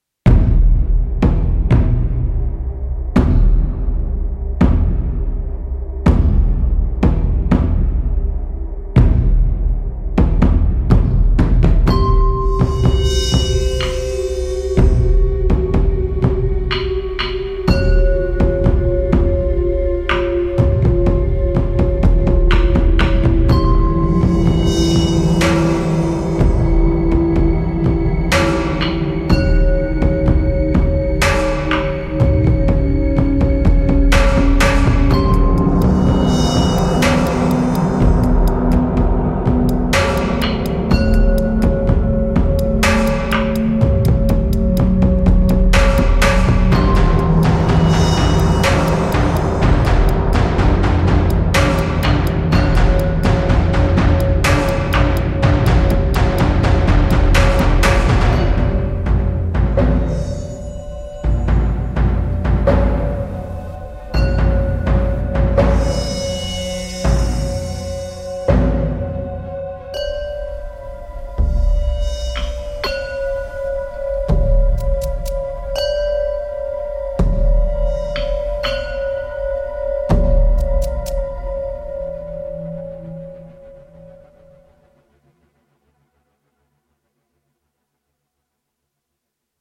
Drums
i love all 3 samples! thrilling.. great deep drums!